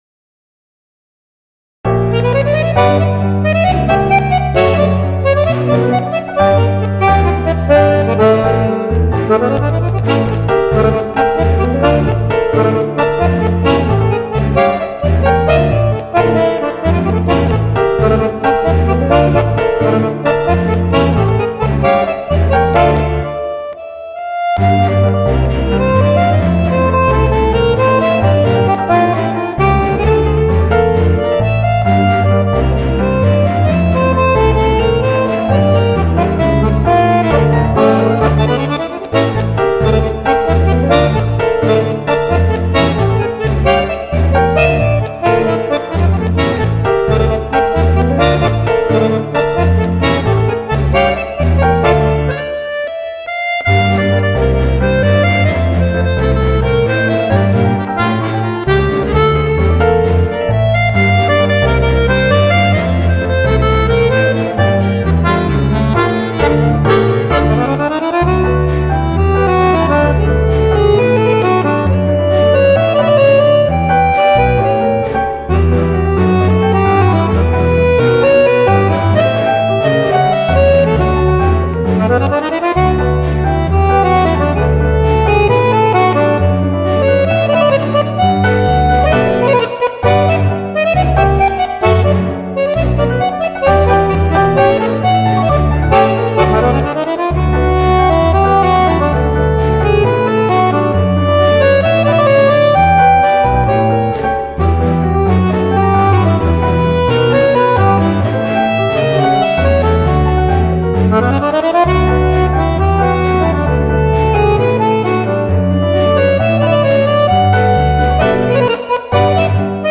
Per Fisarmonica e Pianoforte